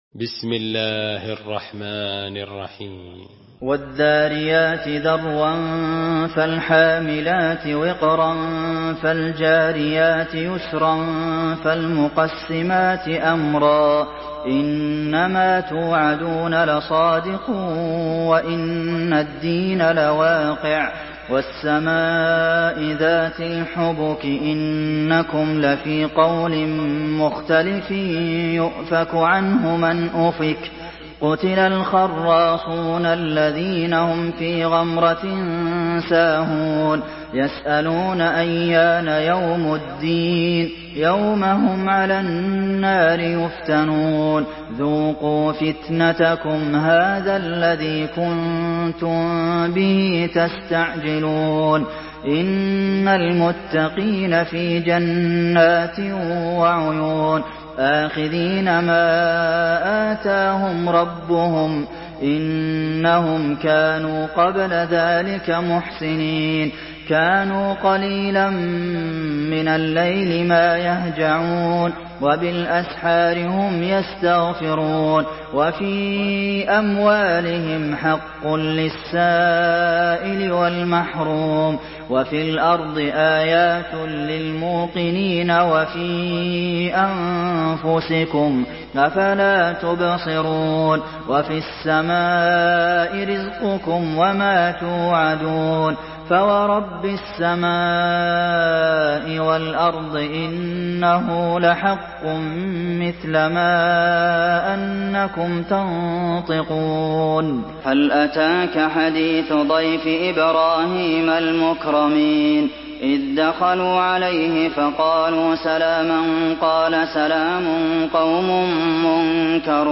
سورة الذاريات MP3 بصوت عبد المحسن القاسم برواية حفص
مرتل